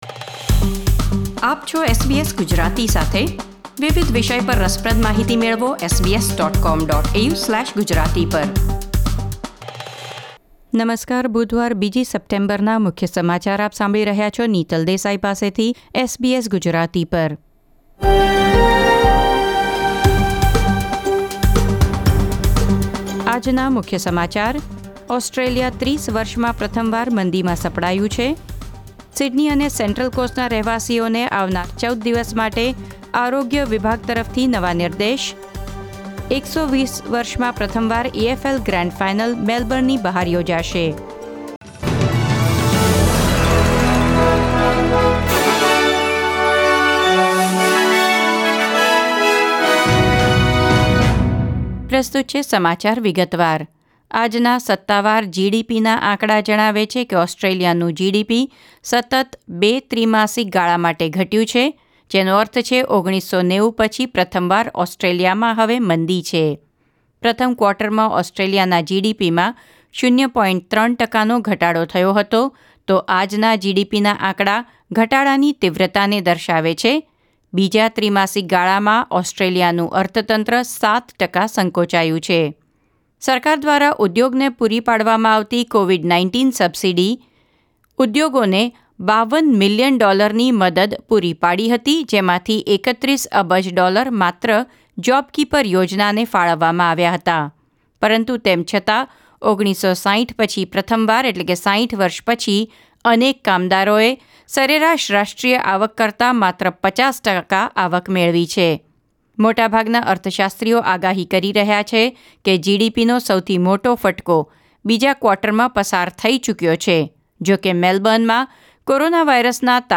SBS Gujarati News Bulletin 2 September 2020